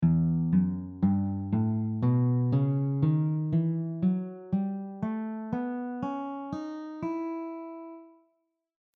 E melodic minor scaleEF#GABC#D#
E melodic minor scale
E-melodic-minor-scale.mp3